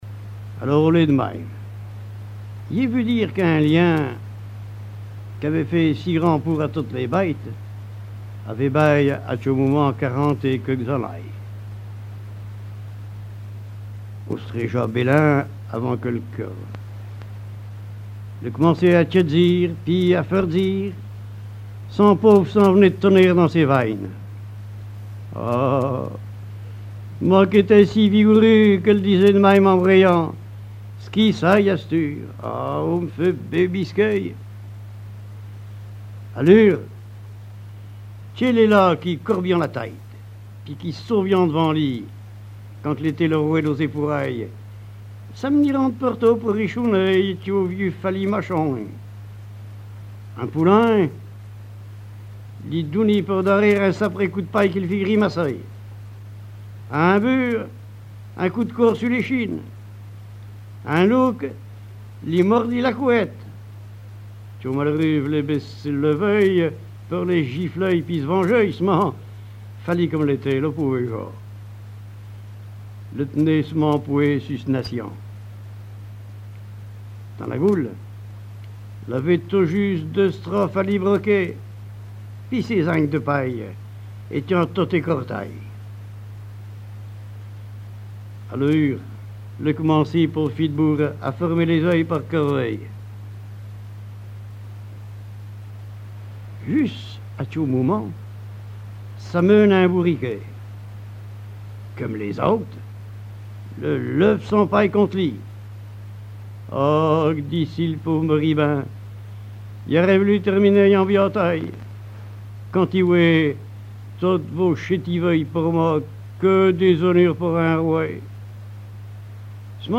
Patois local
Genre fable
Enquête Alouette FM numérisation d'émissions par EthnoDoc
Catégorie Récit